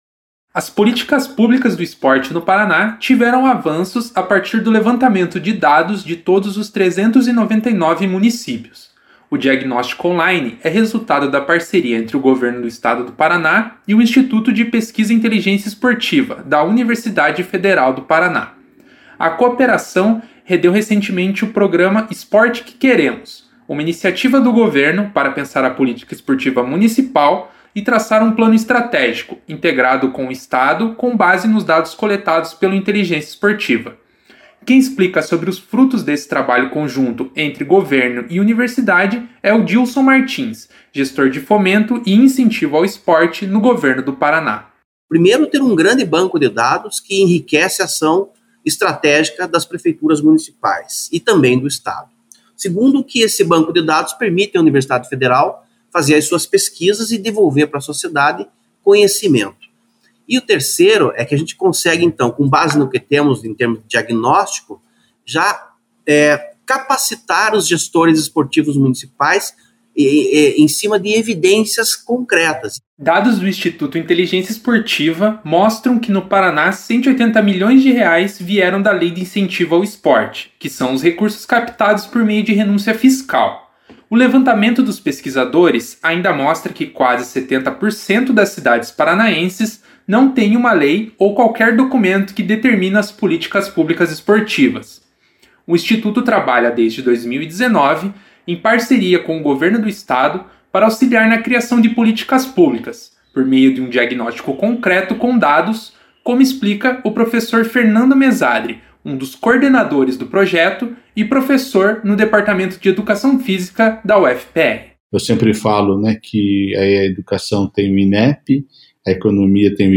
Acompanhe a reportagem que é resultado da parceria Rede Aerp de Notícias e Agência Escola UFPR, da Universidade Federal do Paraná.